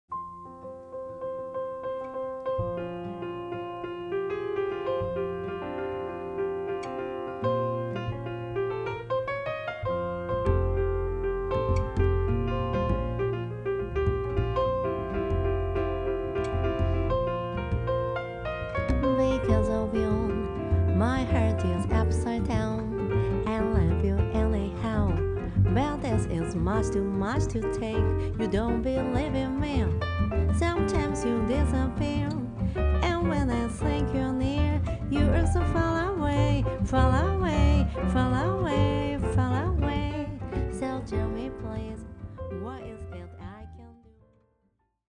Recorded on Dec. 22nd and 23rd, 2024 at Studio Dede, Tokyo